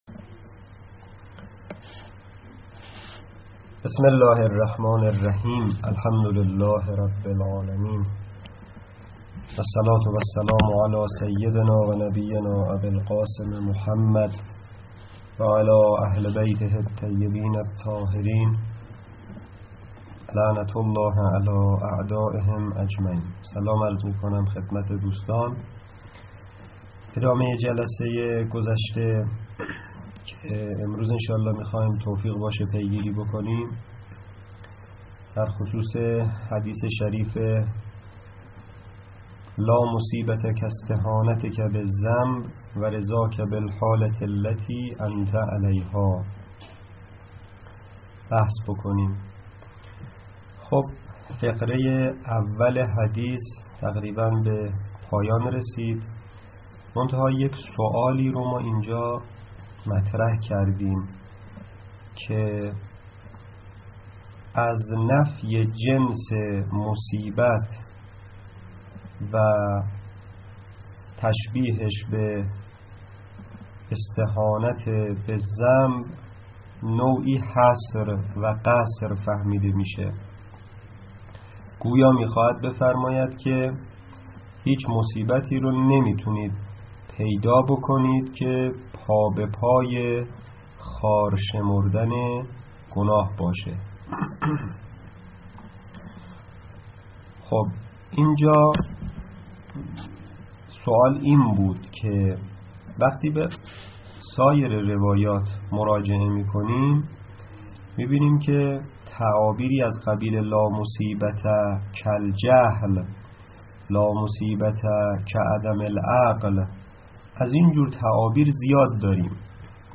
در این بخش، صوت و یا فیلم سخنرانیهای علمی، گفتگوهای علمی، نشست علمی، میزگردها و مناظرات علمی مرتبط با دروس حوزوی درج می‌گردد.